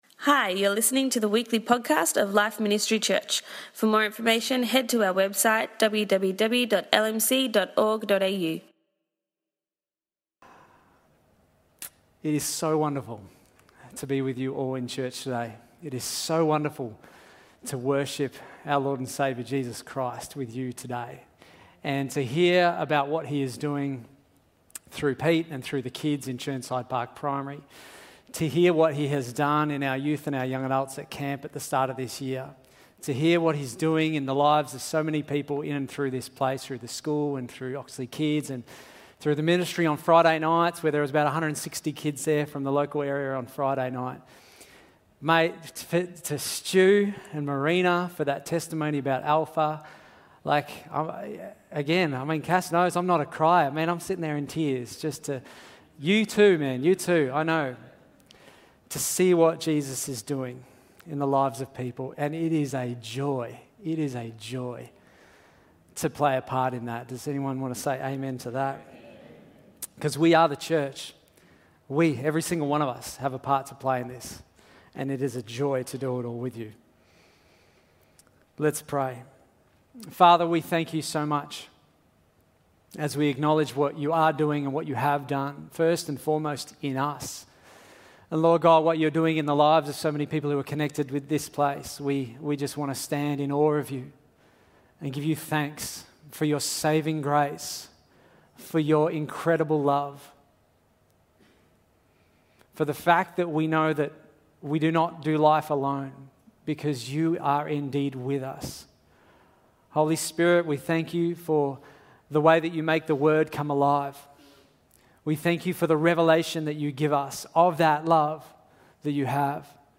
For our Vision Sunday Service